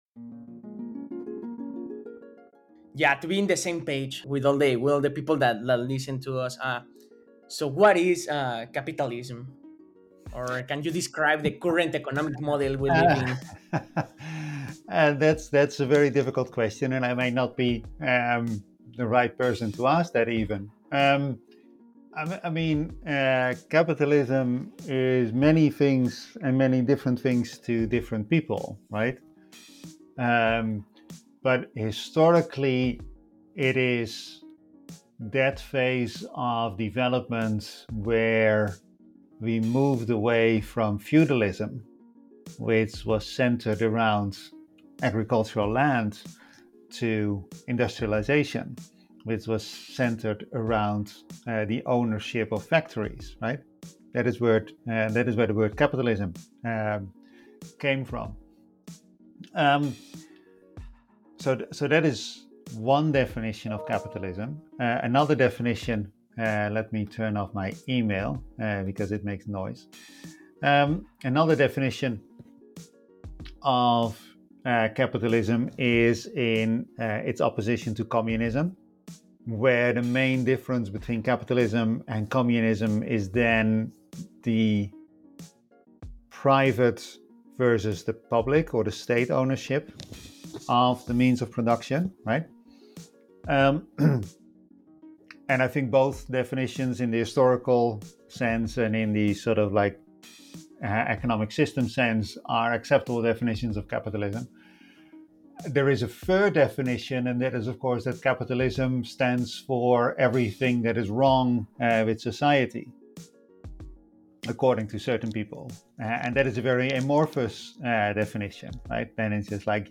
Entrevista completa Dr. Richard Tol - CliMitología - Episodio Capitalismo